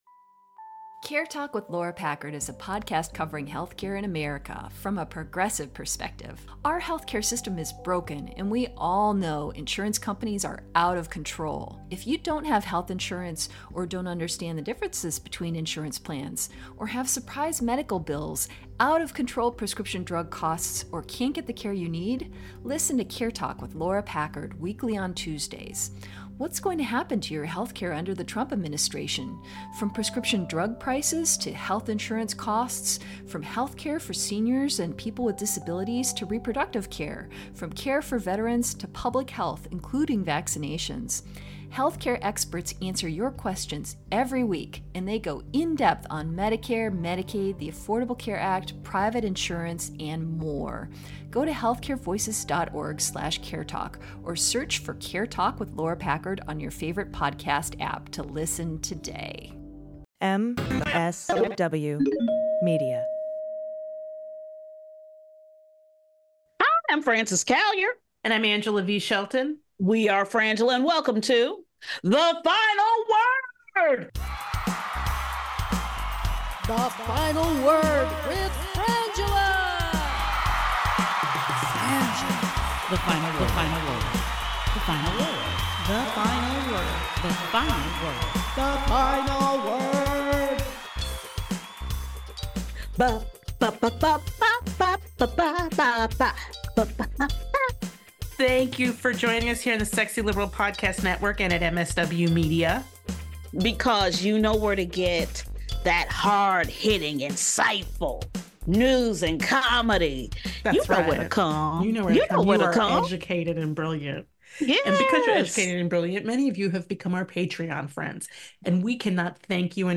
Society & Culture, News, Comedy